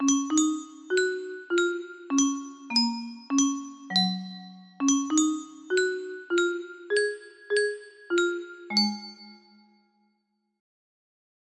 So let me ask just one more time. music box melody
Full range 60